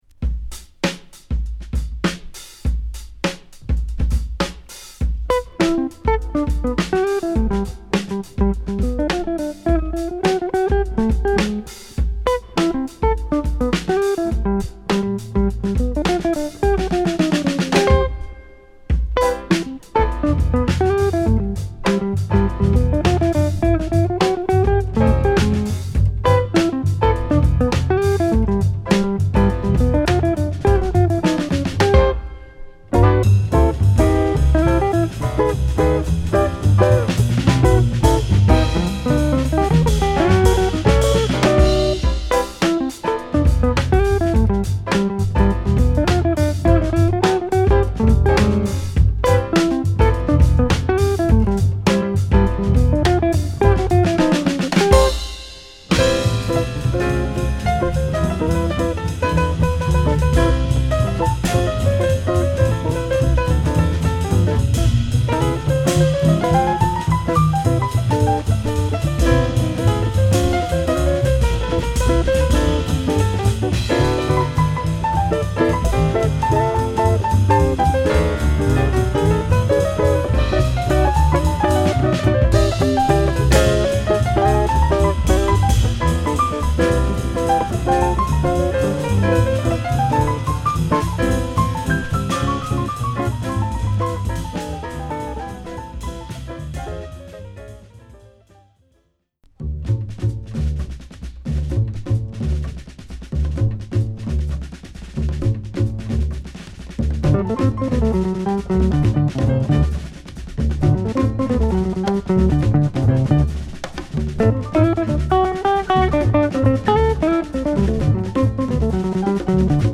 ナイスなドラムブレイクからフュージョンタッチのギターが心地良いA2
爽快で疾走感あるA3